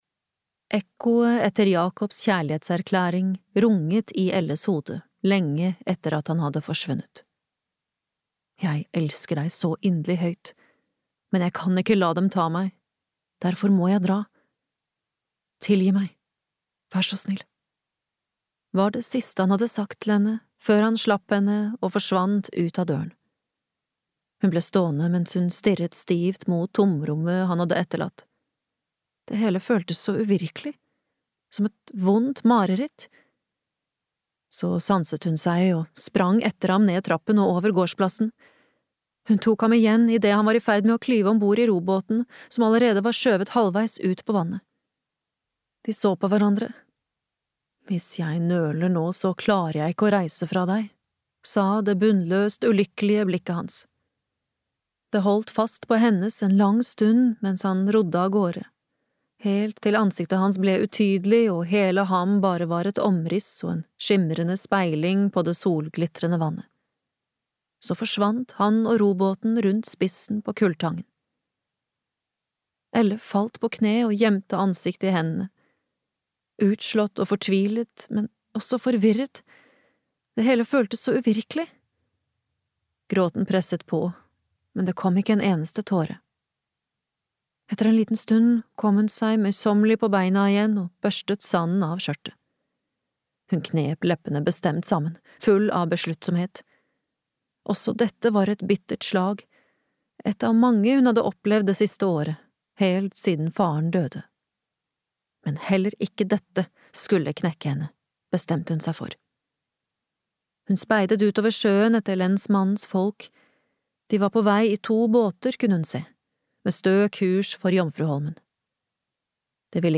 Flukt (lydbok) av Ragnhild Havstad